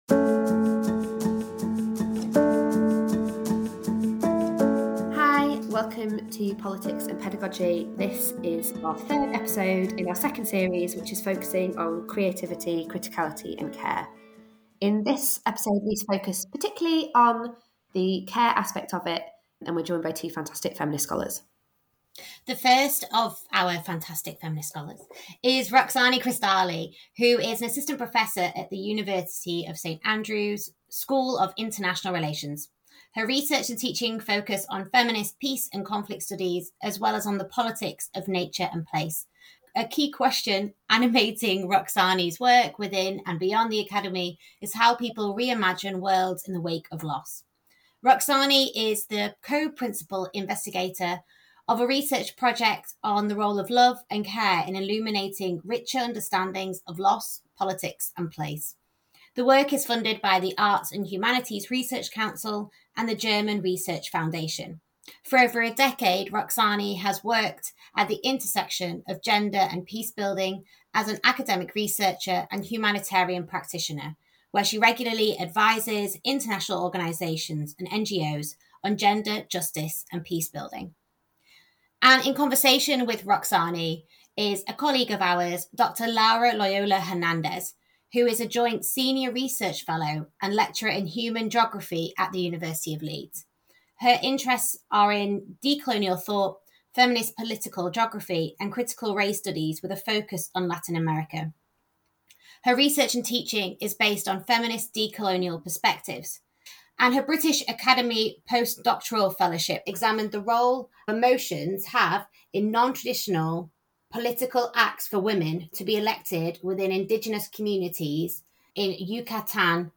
This series showcases conversations from academics in Politics, International Relations and Development as they reflect on decolo